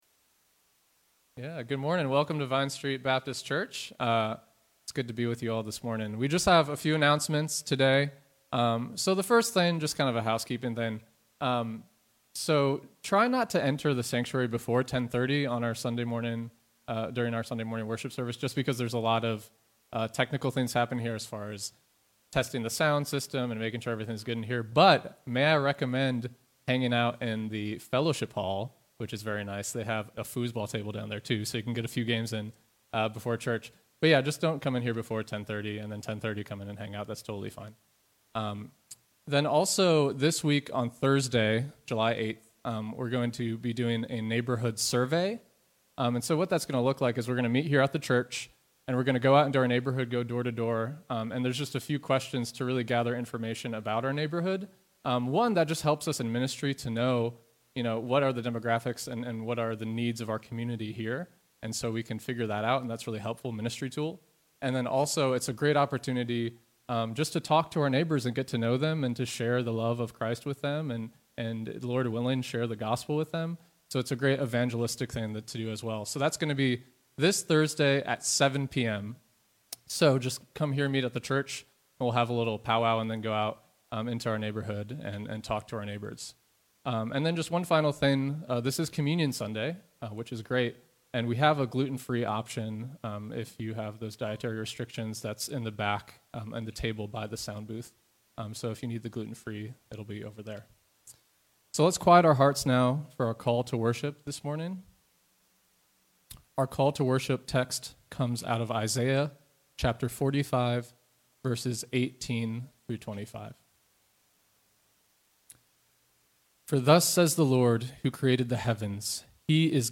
The audio was cut short for this sermon due to a microphone issue.
1 Chronicles 29:10-19 Service Type: Morning Worship The audio was cut short for this sermon due to a microphone issue.